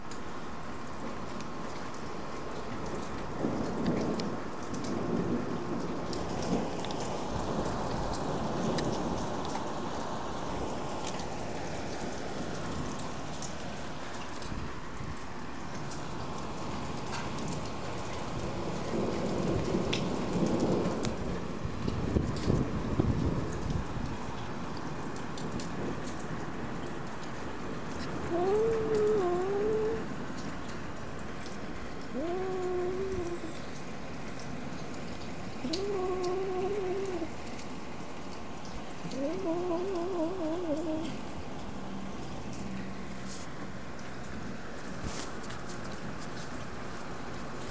Rain, some thunder, and what sounds like a cat having sex.